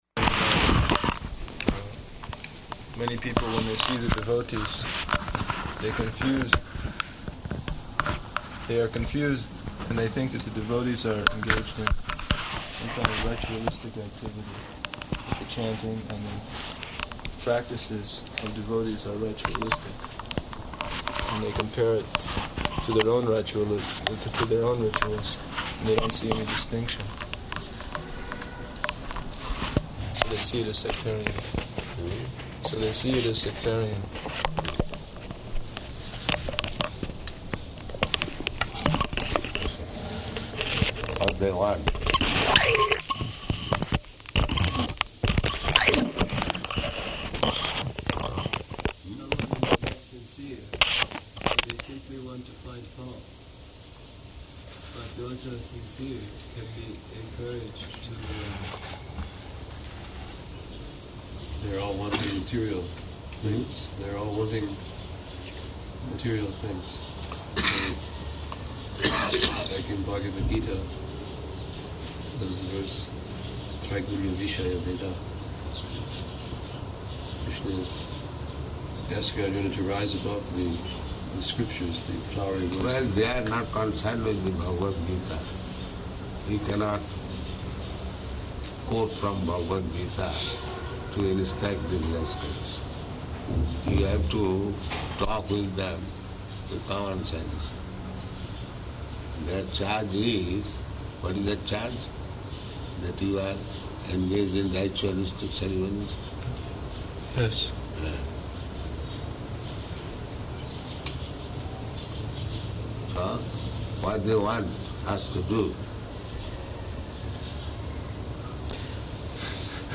Room Conversation
Type: Conversation
Location: Tehran